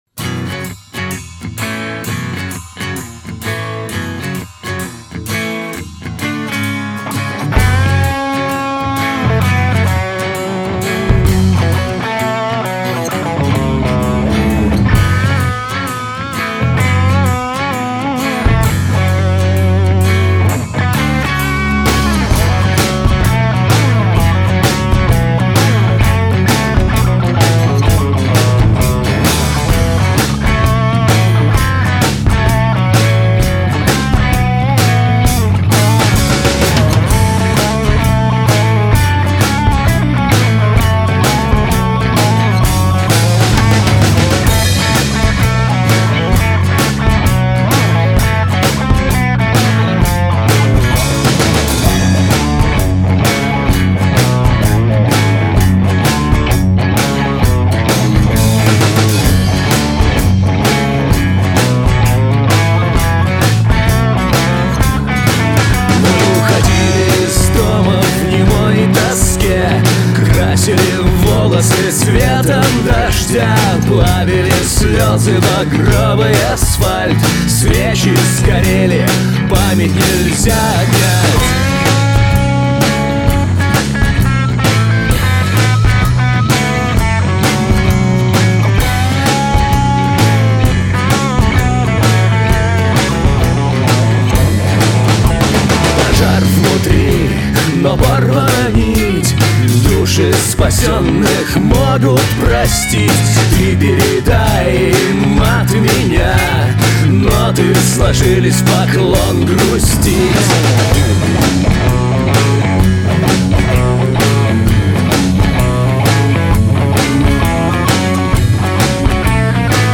вокал, слова, музыка, ритм-гитара, бас.
соло-гитара.
ударные.